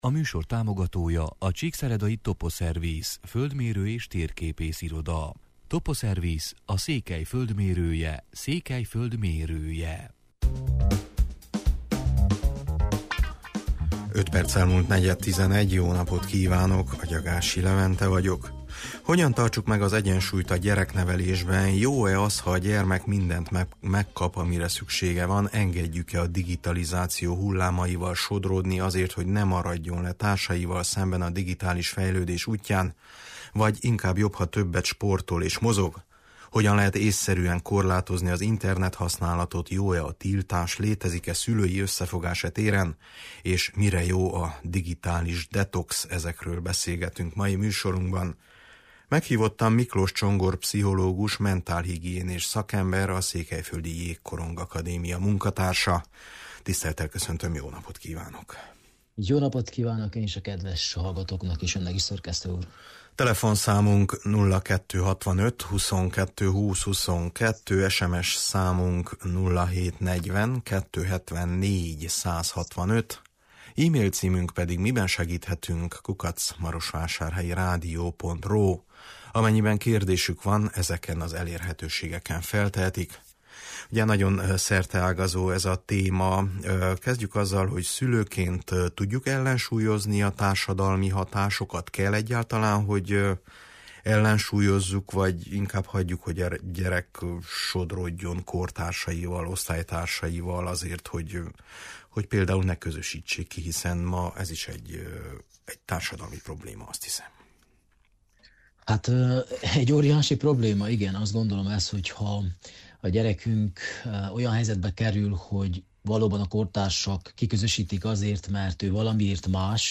Hogyan tartsuk meg az egyensúlyt a gyereknevelésben, jó-e az, ha a gyermek mindent megkap, amire szüksége van, engedjük-e a digtalizáció hullámaival sodródni azért, hogy ne maradjon le társaival szemben a digitalis fejlődés útján, vagy jobb, ha többet mozog és sportol? Hogyan lehet ésszerűen korlátozni az internethasználatot, jó-e a tiltás, létezik-e szülői összefogás e téren, mire jó a digitalis detox? – erről beszélgetünk mai műsorunkban.